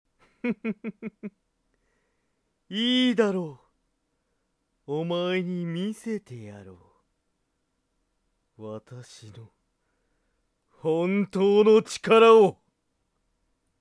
---------------< Sample Voice >---------------
マイク：ＳＯＮＹ　ＥＣＭ−ＭＳ９０７